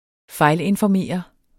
Udtale [ ˈfɑjlenfɒˌmeˀʌ ]